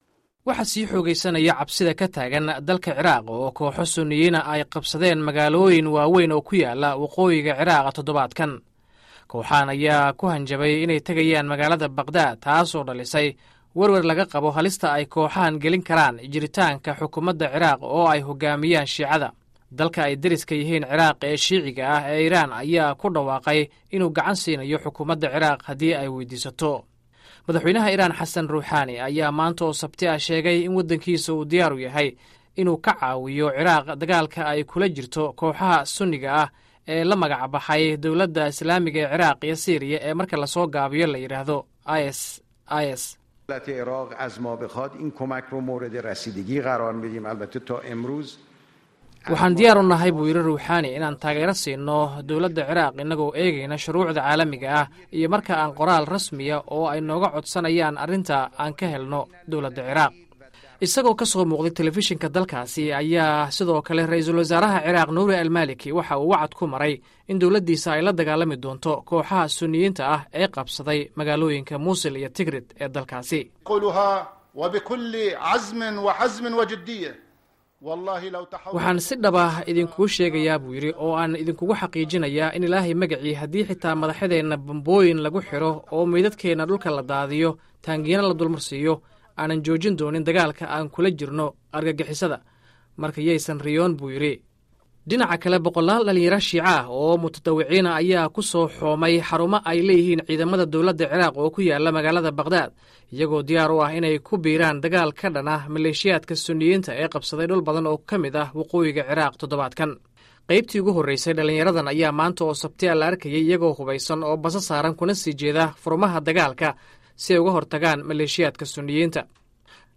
Warbixinta Ciraq